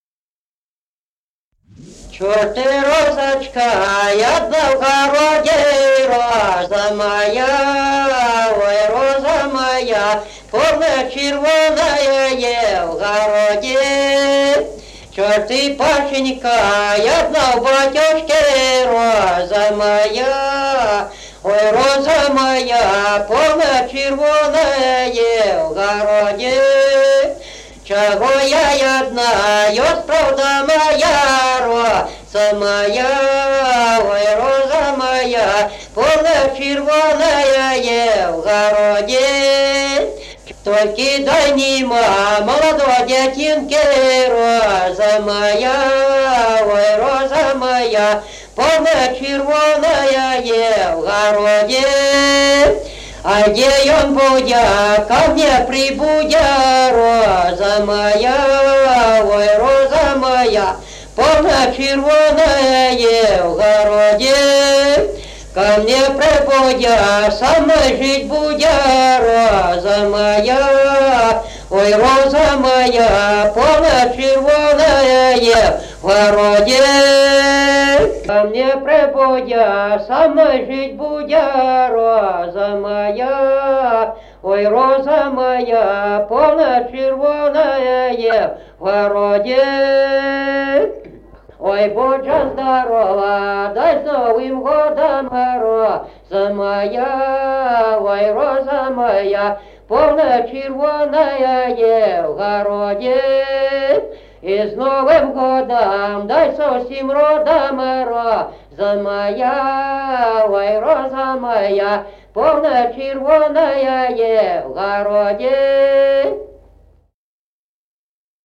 Народные песни Стародубского района «Что ты, розочка», новогодняя щедровная.
1953 г., д. Камень.